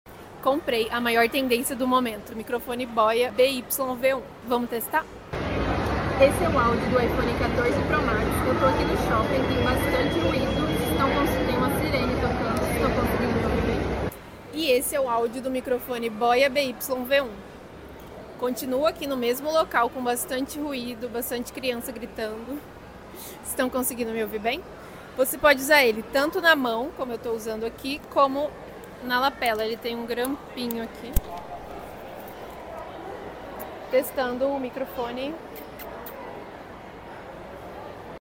Testei o Boya BY-V1 🎙 microfone de lapela com custo benefício toppp!